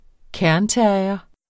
Udtale [ ˈkæɐ̯n- ]